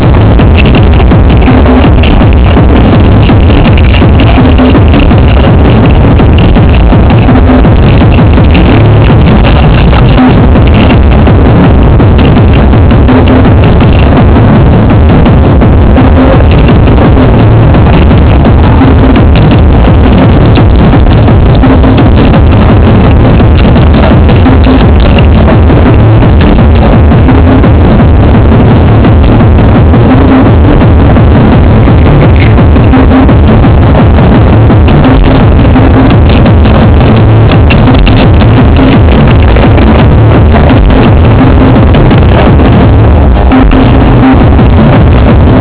Le teknival c’est un grand rassemblement de musique électronique, dégustation de plats épicés, boissons enivrantes et plus si affinités. Cette musique envahi le corps à en faire perdre le rythme cardiaque, une danse individuelle à faire oublier les 40000 personnes autour.